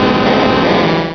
Cri d'Onix dans Pokémon Rubis et Saphir.